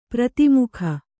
♪ prati mukha